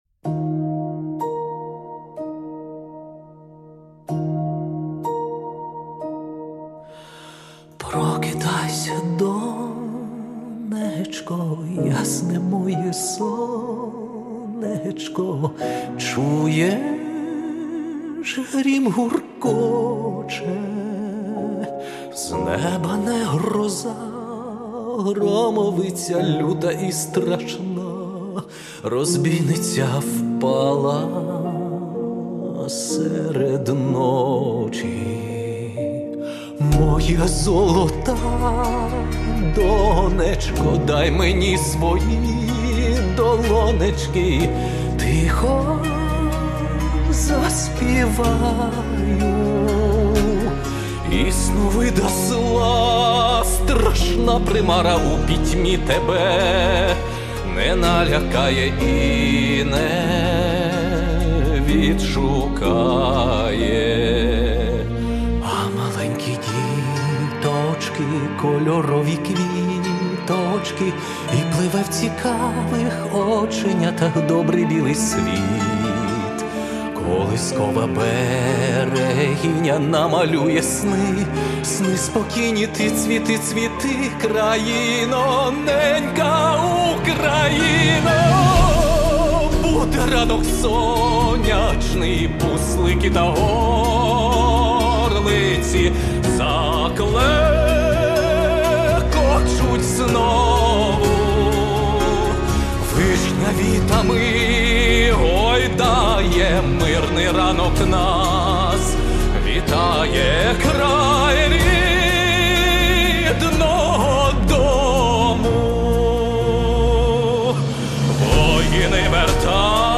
кавер пісні